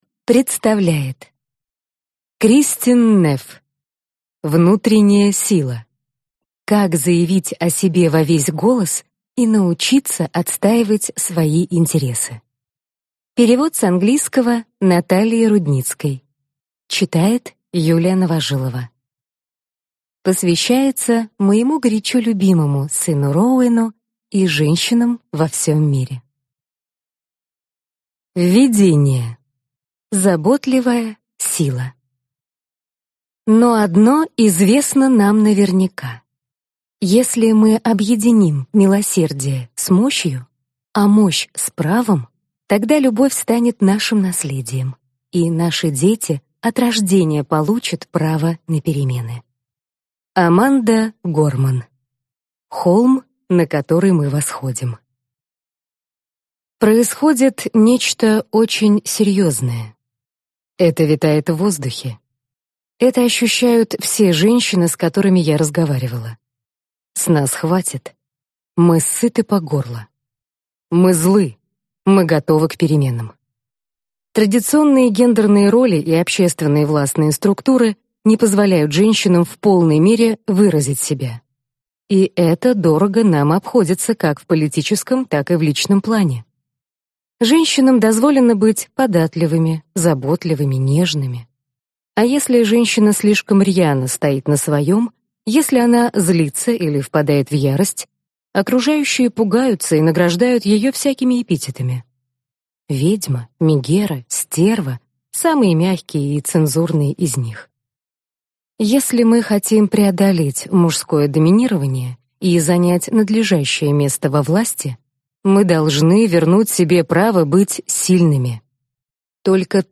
Аудиокнига Внутренняя сила. Как заявить о себе во весь голос и научиться отстаивать свои интересы | Библиотека аудиокниг